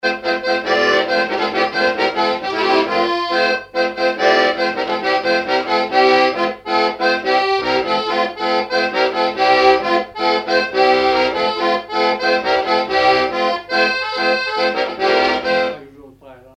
Localisation Noirmoutier-en-l'Île (Plus d'informations sur Wikipedia)
Fonction d'après l'analyste danse : branle : courante, maraîchine ;
Genre brève
Catégorie Pièce musicale inédite